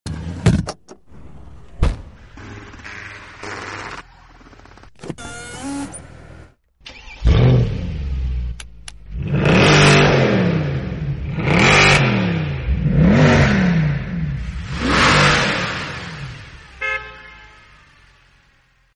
( $355K ) ASMR Mercedes sound effects free download